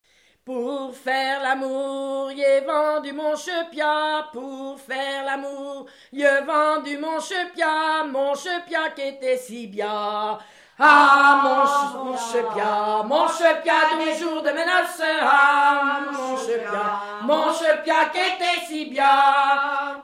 Genre énumérative
chansons traditionnelles
Pièce musicale inédite